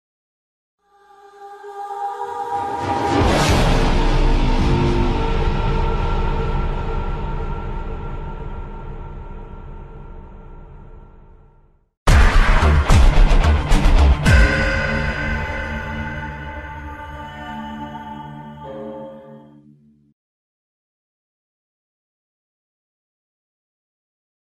game